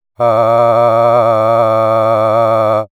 Physics-based simulation of disordered vocal timbres generated by SimuVox for clinical training and perceptual research.
Tremor:
• Tremor: Simulated through low-frequency modulation of subglottal pressure and laryngeal muscle tension
male_tremor.wav